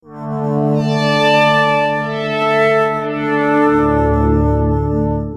SONS ET SAMPLES DU SYNTHÉTISEUR OBERHEIM MATRIX 1000